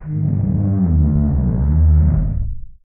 MOAN EL 04.wav